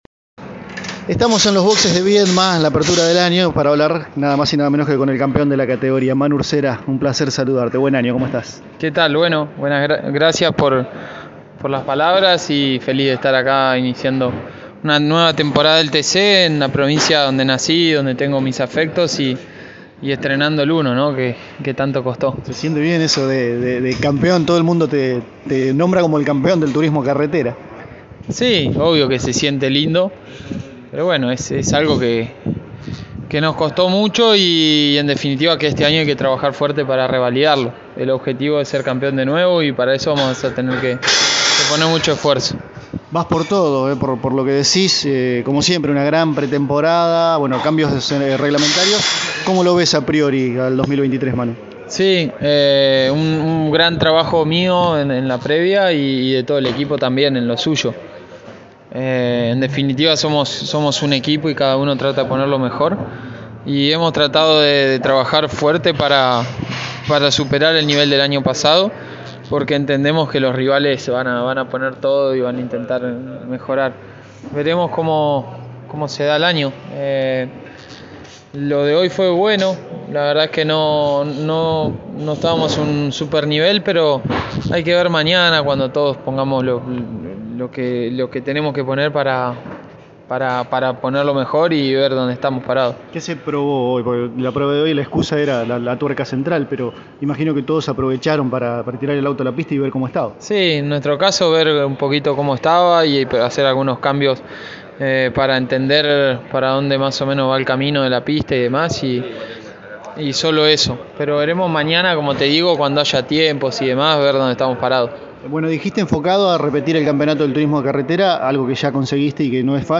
El campeón del Turismo Carretera paso por los micrófonos de Pole Position y habló sobre el funcionamiento que tuvo el Torino en los ensayos no oficiales que tuvo la categoría en Viedma. Habló sobre su año deportivo en Turismo Nacional y TC Pickup, detallando cómo fue su preparación en el exterior.